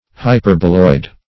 Hyperboloid \Hy*per"bo*loid\, n. [Hyperbola + -oid: cf. F.